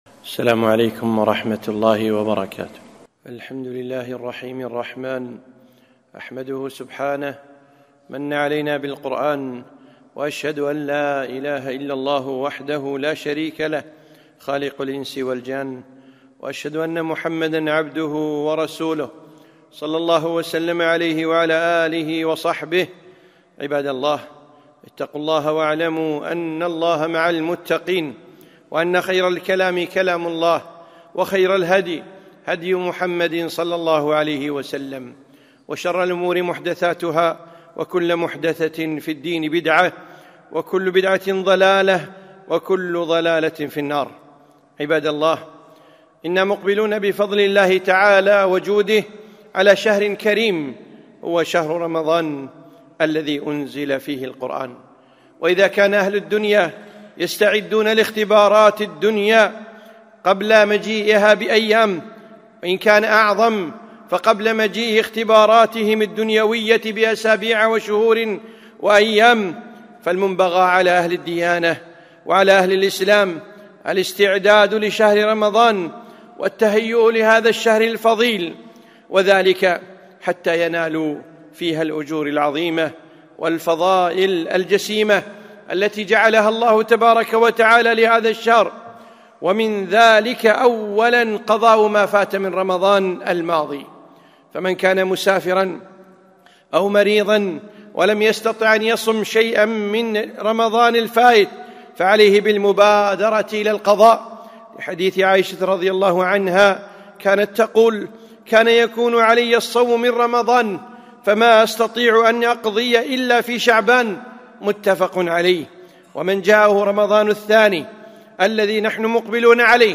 خطبة - الاستعداد لرمضان